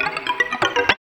78 GTR 4  -L.wav